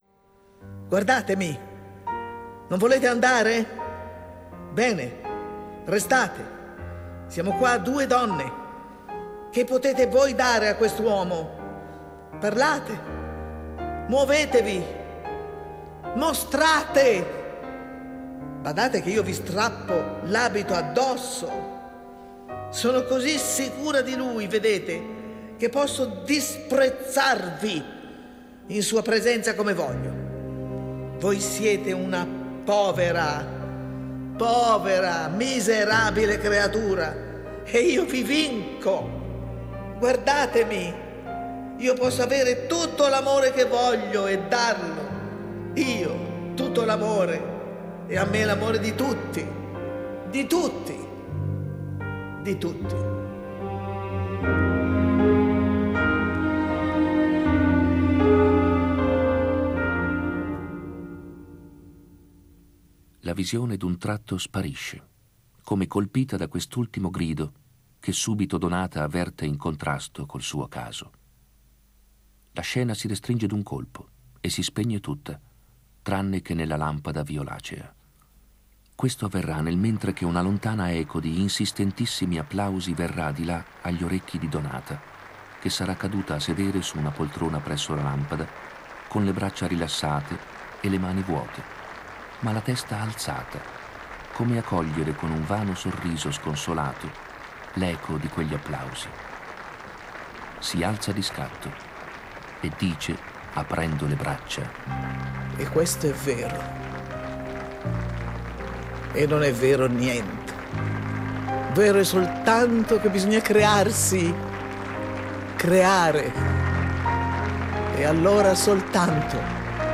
La mitica voce di Marta Abba